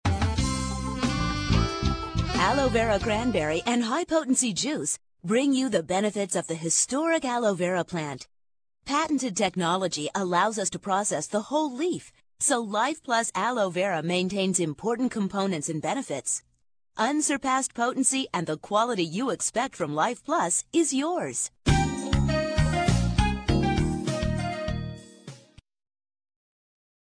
Audio Demos - Message On Hold